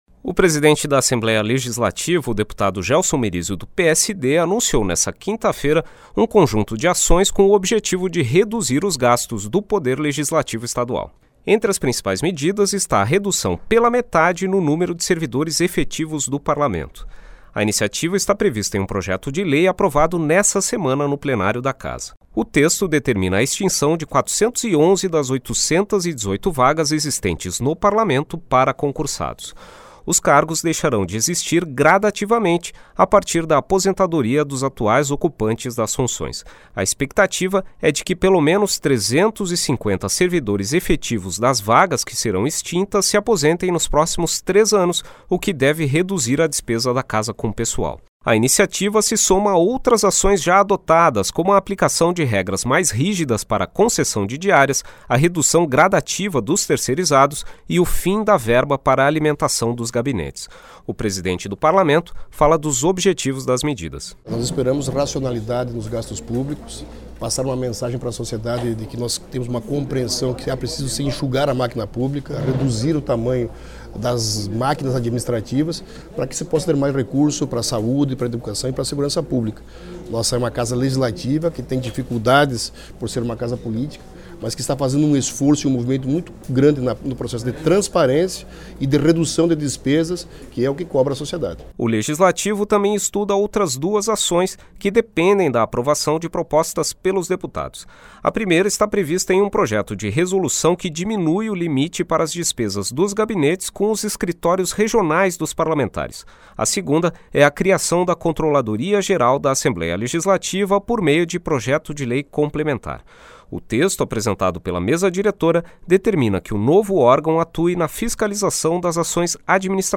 Ações foram divulgadas em entrevista coletiva, concedida para a imprensa nesta quinta-feira (29).